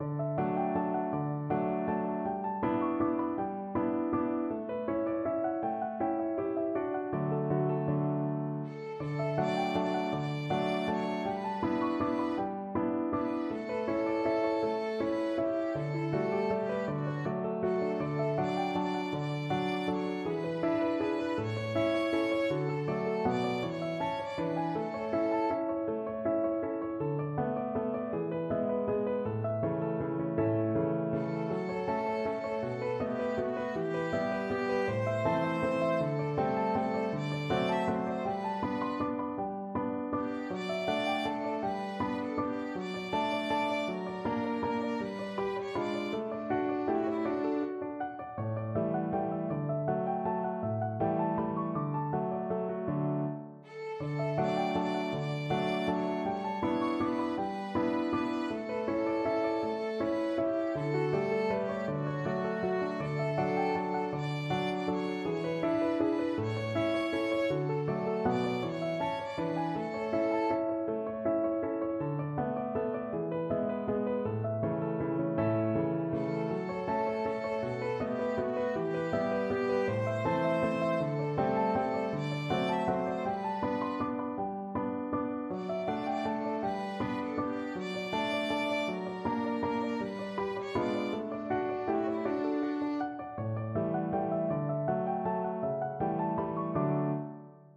Classical Mozart, Wolfgang Amadeus Deh, vieni from Don Giovanni Violin version
Violin
= 80 Allegretto
6/8 (View more 6/8 Music)
D major (Sounding Pitch) (View more D major Music for Violin )
Classical (View more Classical Violin Music)